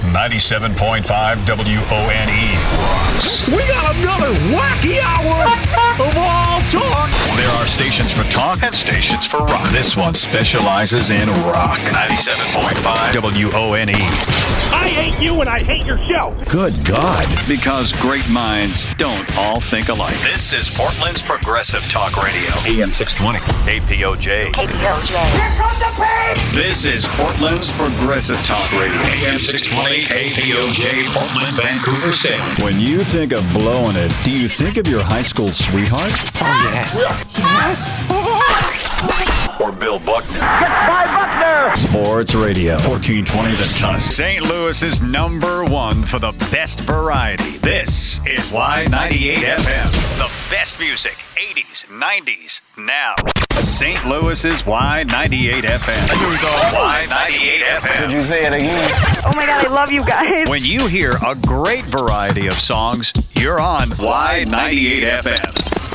Imaging VO Demo